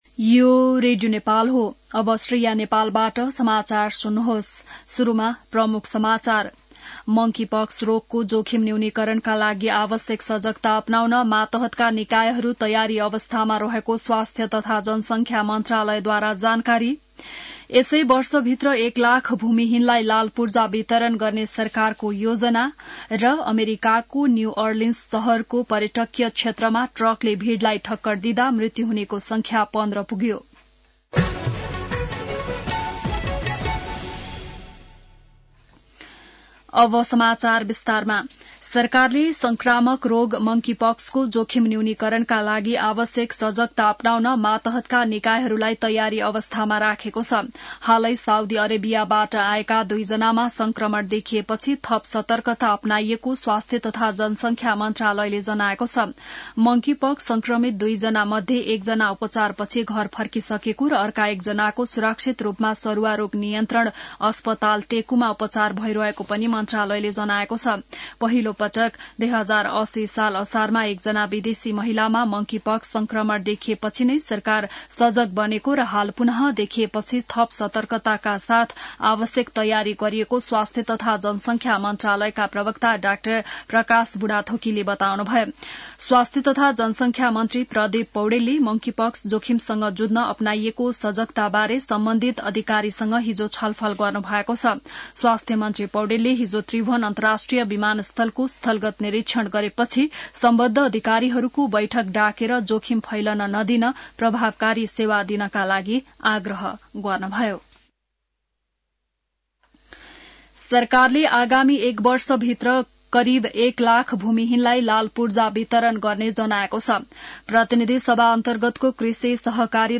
An online outlet of Nepal's national radio broadcaster
बिहान ९ बजेको नेपाली समाचार : १९ पुष , २०८१